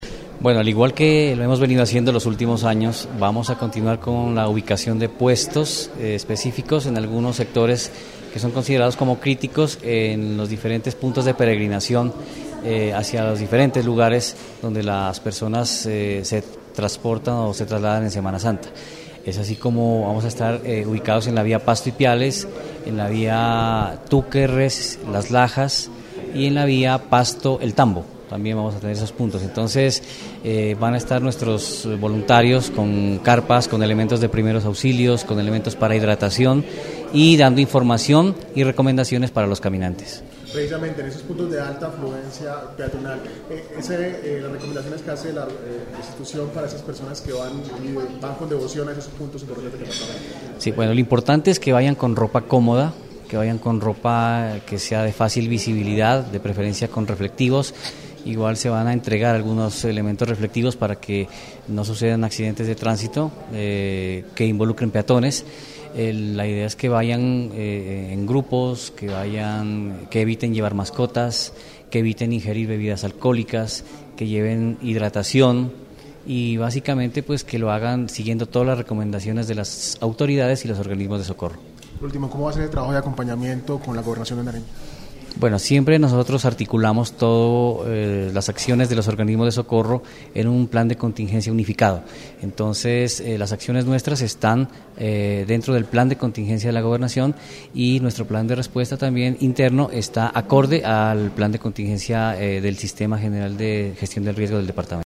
En rueda de prensa cumplida en el Hotel Agualongo de Pasto, se socializaron diversas recomendaciones dirigidas a los actores de la movilidad, quienes durante la Semana Mayor toman parte de múltiples actividades religiosas, como por ejemplo, las romerías y peregrinaciones al Santuario de Las Lajas en Ipiales, Santuario de Jesús Nazareno en El Tambo, Santuario de la Virgen de la Playa en San Pablo, entre otros.